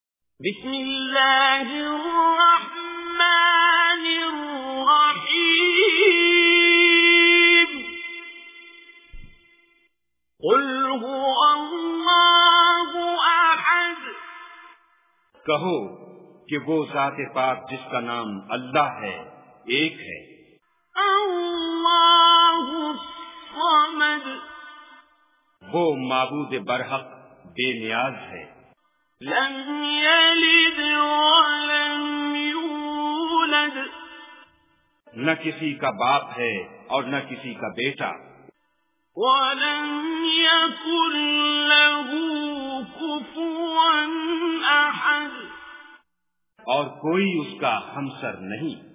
Surah Al-Ikhlas Recitation with Urdu Translation
Surah Al-Ikhlas is 112 chapter / Surah of Holy Quran. Listen online and download mp3 tilawat / recitation of Surah Al-Ikhlas in the voice of Qari Abdul Basit As Samad.